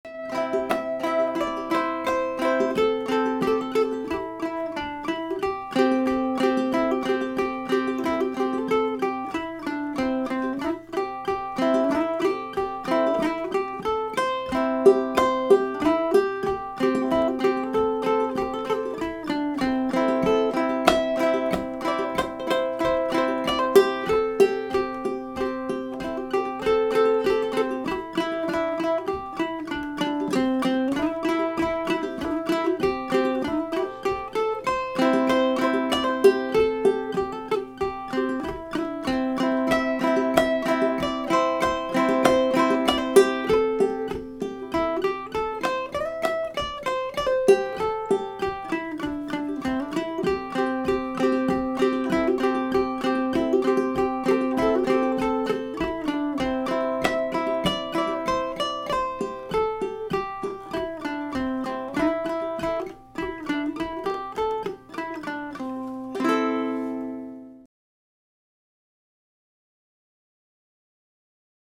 He recorded this using his Zoom H2 microphone.